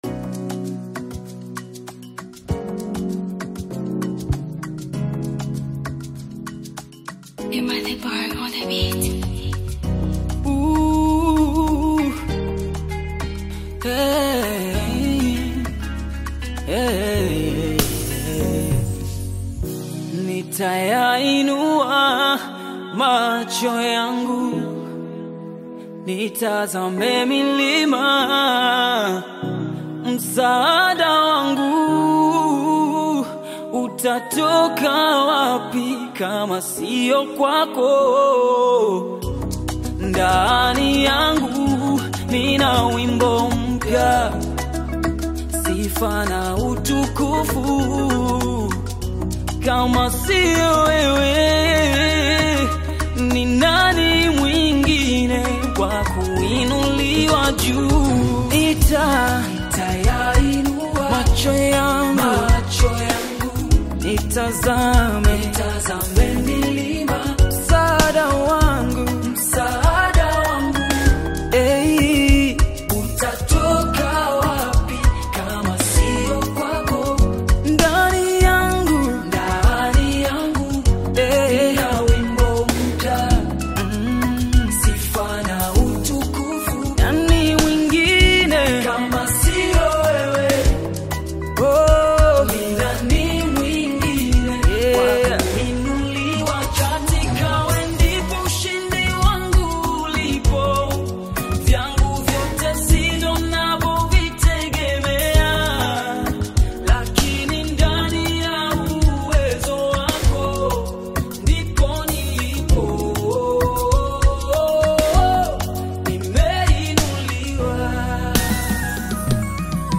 A hopeful and inspirational gospel single
is an inspiring Tanzanian gospel single
soulful vocal delivery
spiritually uplifting gospel sound